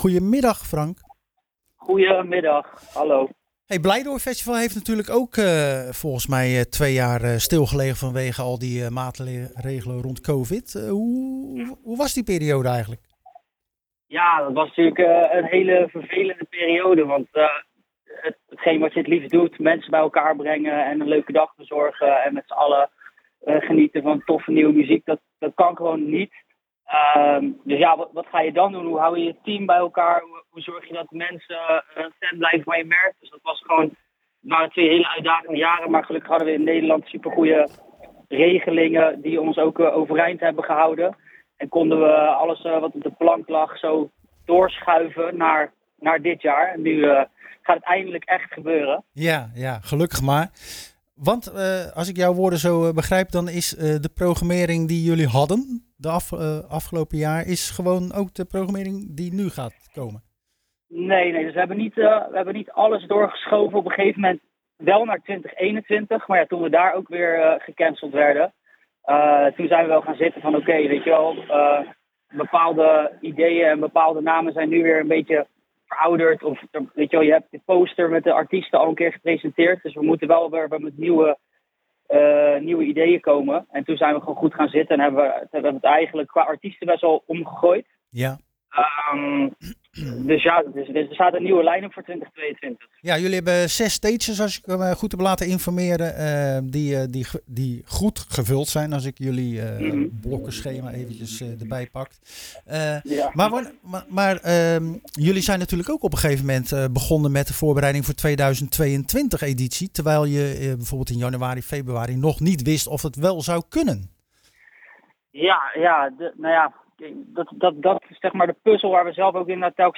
Zoals ieder jaar belde we ook dit jaar weer met organisator